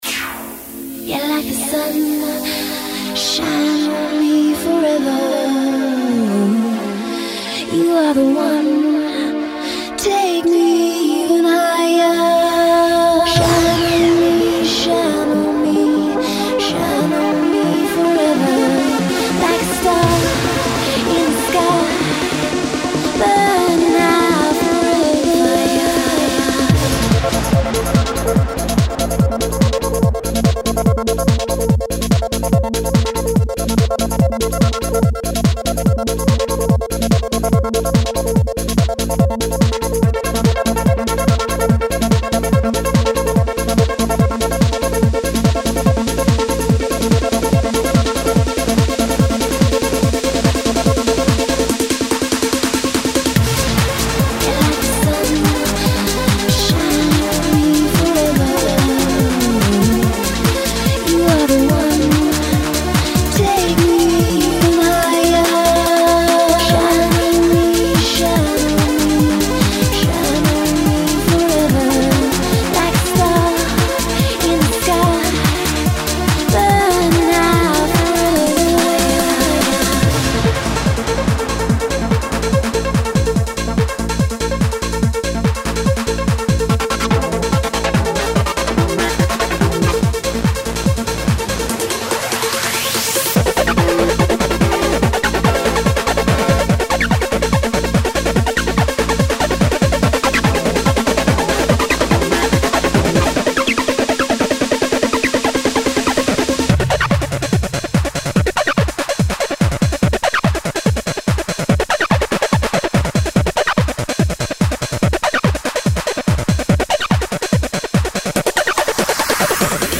Techno
Trance